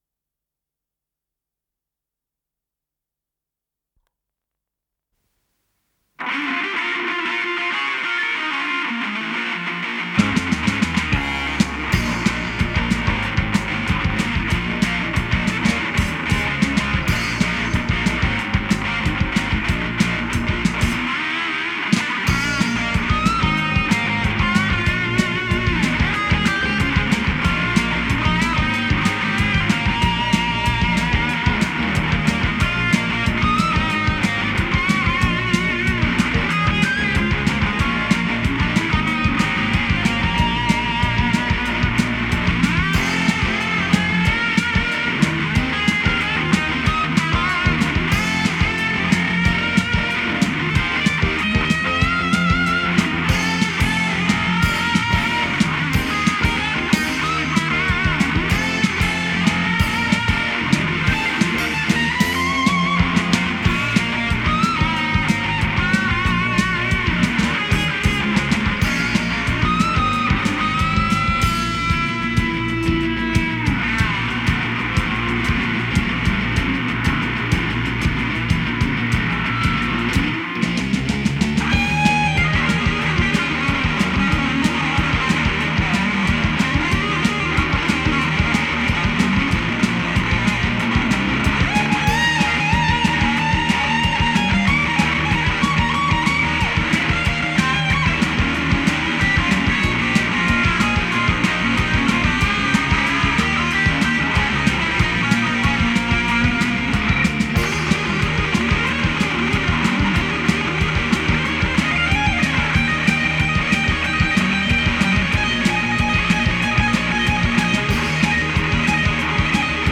с профессиональной магнитной ленты
электрогитара
АккомпаниментИнструментальный ансамбль